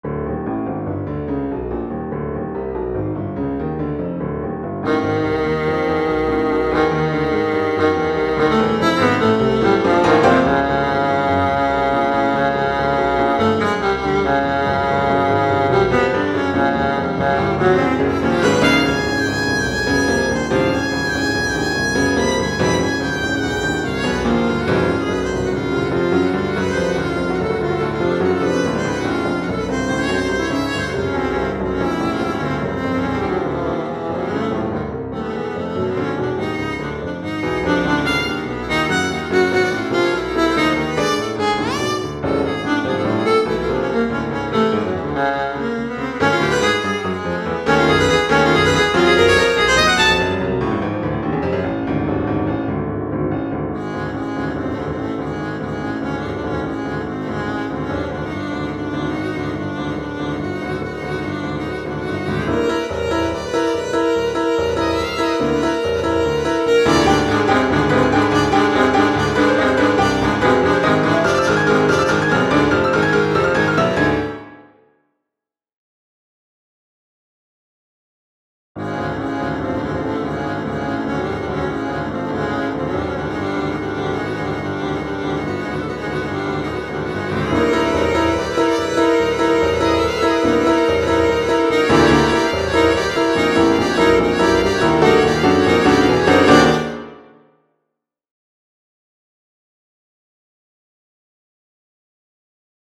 Here is audio for the first movement of the viola sonata.
The main theme comes back, but it is played in fifths by the viola.
I. Agitato